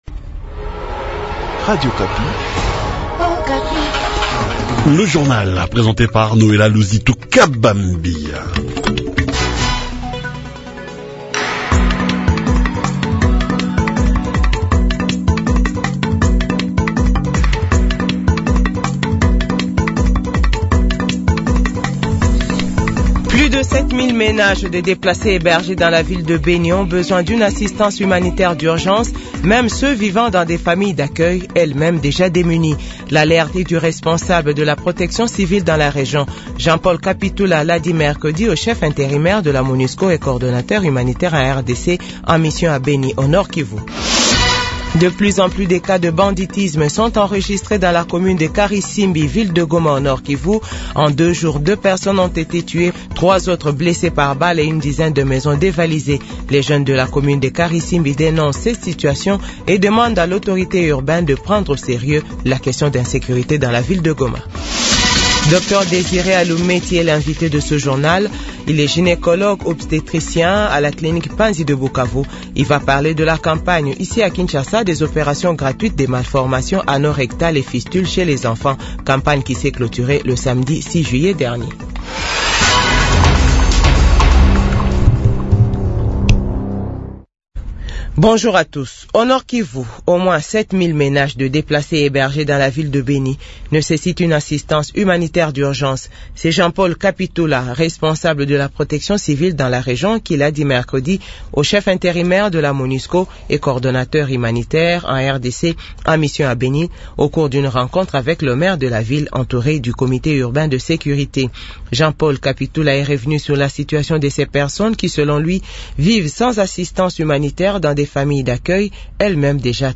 Journal Français 8h00 du vendredi Juillet 2024